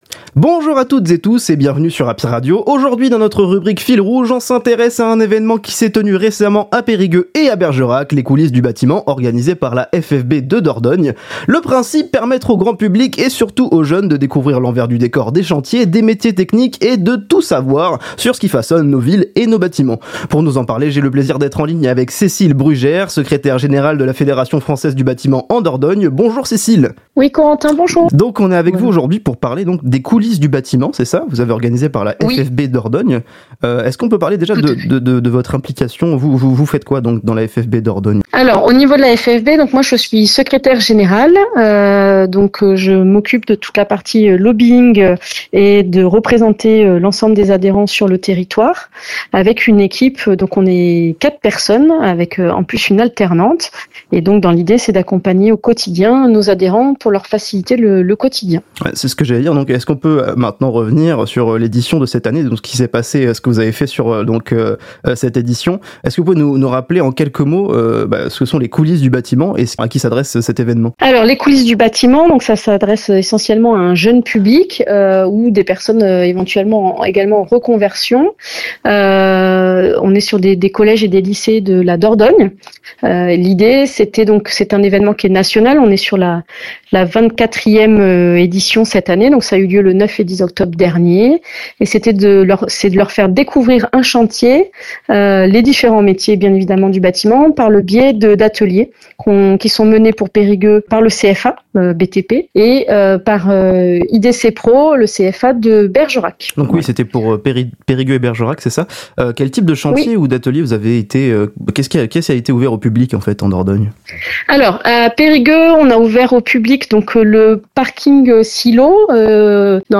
Les Interviews Happy Radio – Les Coulisses du Bâtiment avec la FFB Dordogne